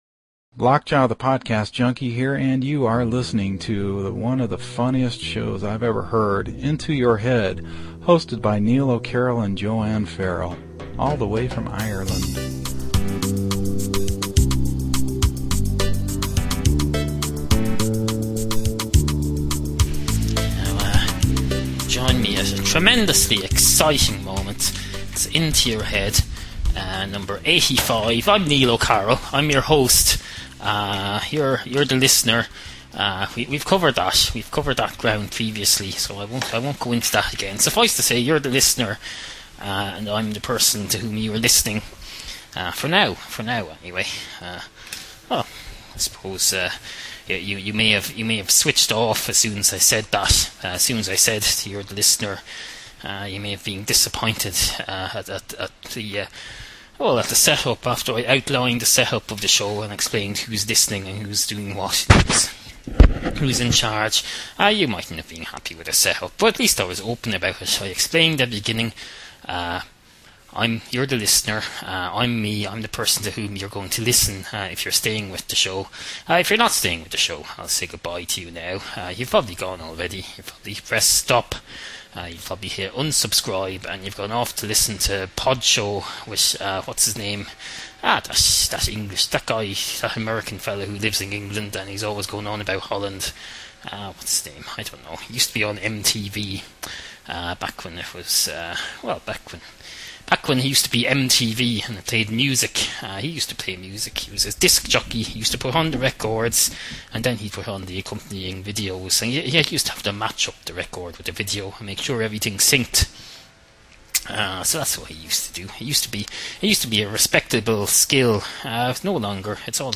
Tonight’s Topics Include: Turning off the listener, Automated video jockeys, Rowan and Martin’s name swap, An unplanned intelligence test, Power efficiency on a 1980s bicycle, Limiting your knowledge intake, An imaginary capital of Afghanistan, The millennium of the past, Large hand means large cat, Reading a deity’s palm, Sourcing protein and water on the moon, Overly-conscious participants in analogies and sit-coms, Chilli’s “snip”, One-touch music creation, Some filler about institutions or something and more. Also: A special musical number to mark Chilli the cat’s upcoming operation.